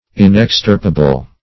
Inextirpable \In`ex*tir"pa*ble\, a. [L. inexstirpabilis: cf. F.